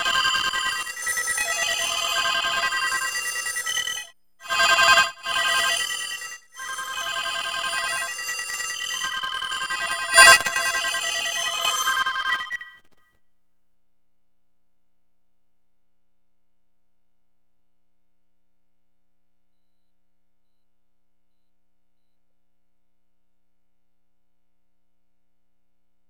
Index of /90_sSampleCDs/E-MU Producer Series Vol. 3 – Hollywood Sound Effects/Human & Animal/Phone Collage
PHONE COL02R.wav